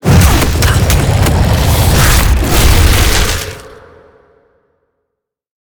Sfx_creature_chelicerate_soloattack_01.ogg